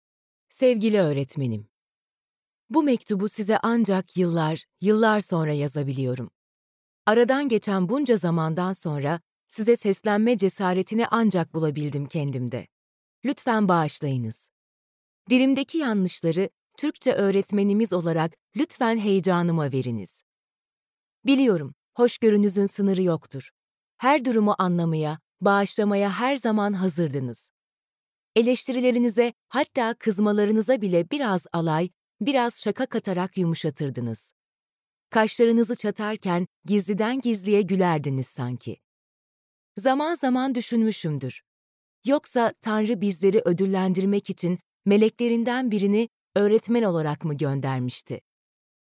Sesli Kitap
Deneyimli seslendirme sanatçılarının okuduğu, editörlüğümüz tarafından özenle denetlenen sesli kitap koleksiyonumuzun ilk örneklerini paylaşmaktan sevinç duyuyoruz.
Öyküler